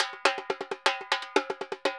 Repique Salsa 120_1.wav